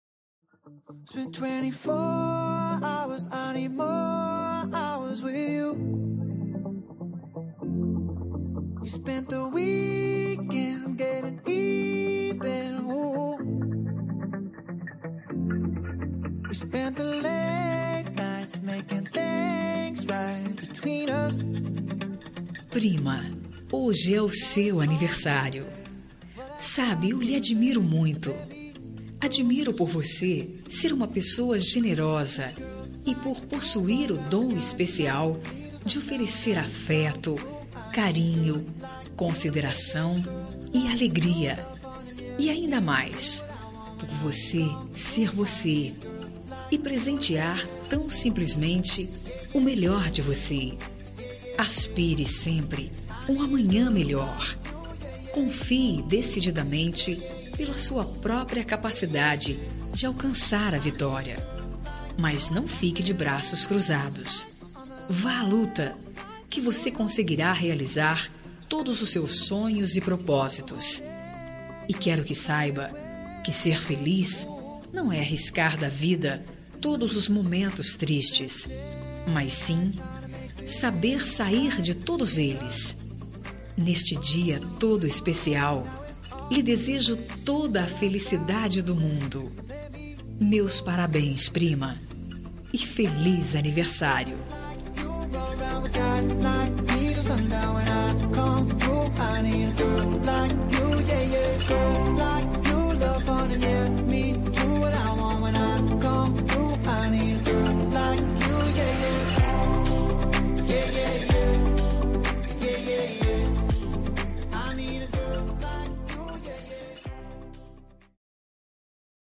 Aniversário de Prima – Voz Feminina – Cód: 042809 – Distante